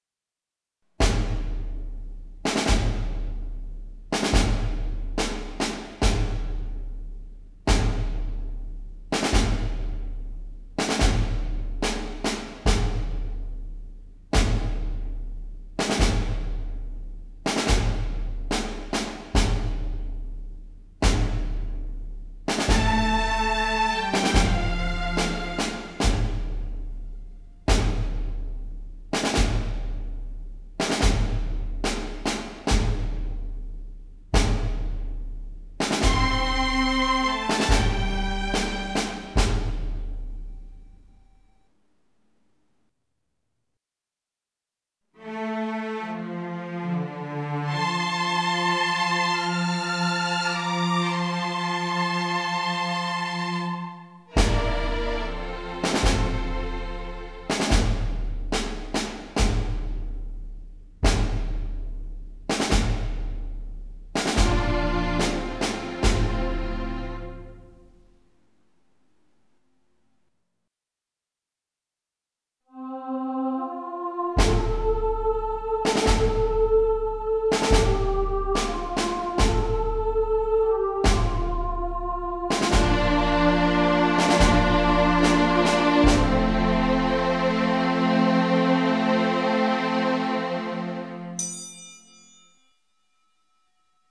This arrangement was created for a church service on Palm Sunday. The backing track includes percussion, strings, and some vocal "oohs" on the last phrase.
were_you_there_backing_track.mp3